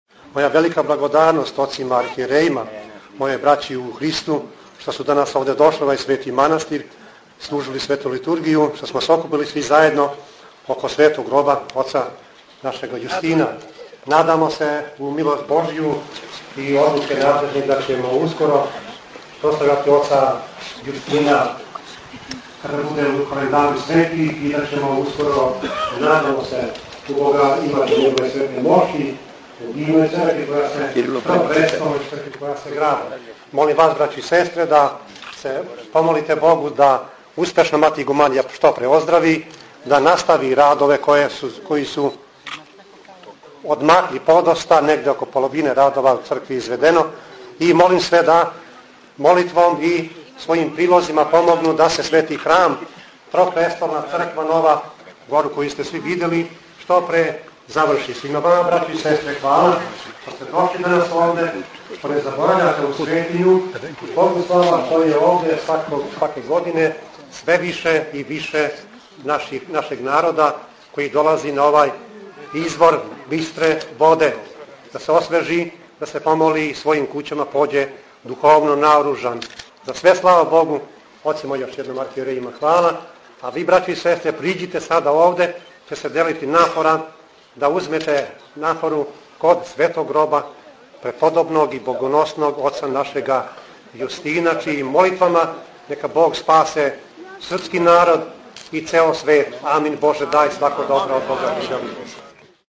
Код гроба аве Јустина пререзан је славски колач, а присутне је, на том светом месту поклоњења вернога народа, поздравио Епископ Милутин.
Звучни запис беседе Епископа Милутина
EpMilutin_Celije_2010.mp3